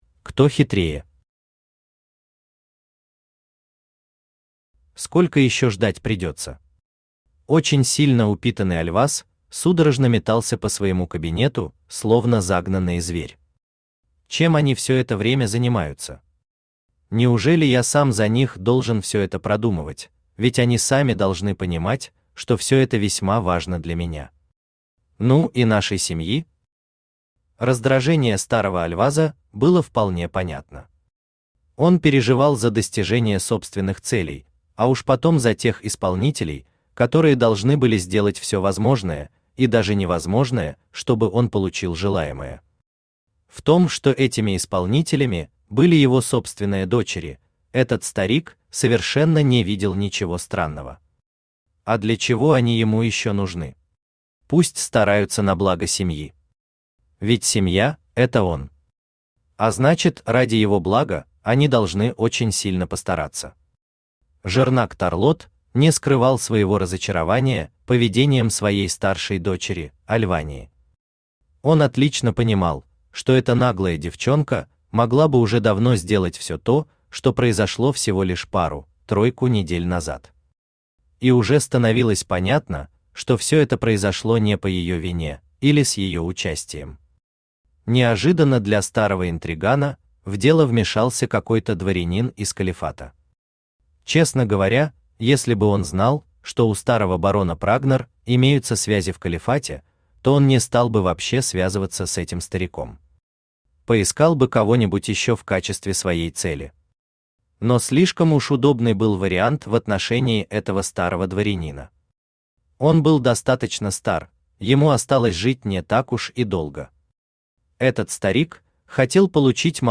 Аудиокнига Колонист. Часть 6. Паутина интриг | Библиотека аудиокниг
Паутина интриг Автор Хайдарали Усманов Читает аудиокнигу Авточтец ЛитРес.